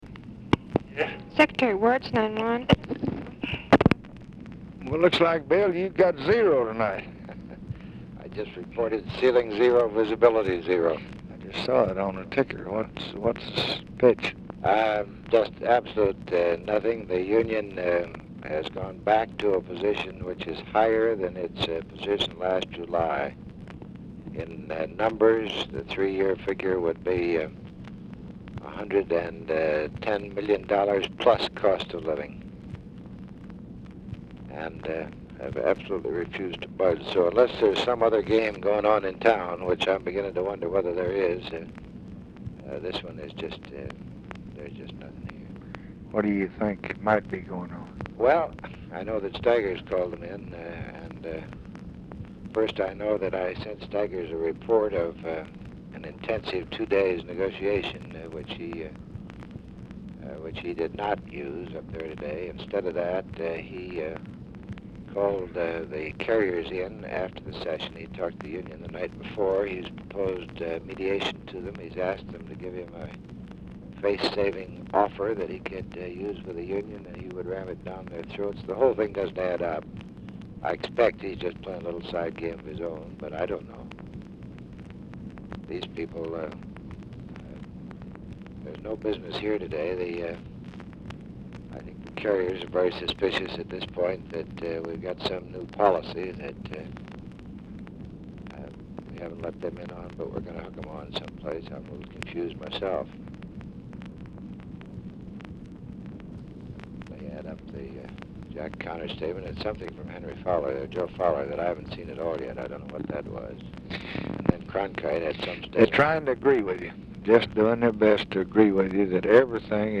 Telephone conversation # 10593, sound recording, LBJ and WILLARD WIRTZ, 8/8/1966, 10:55PM | Discover LBJ
Format Dictation belt
Location Of Speaker 1 Oval Office or unknown location